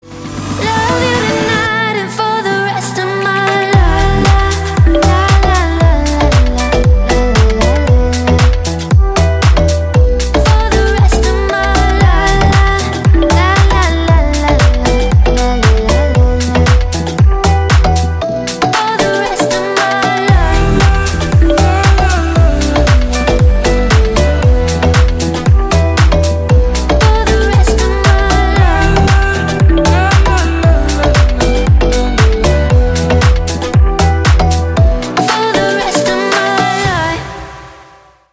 женский голос
Electronic
EDM
попса
house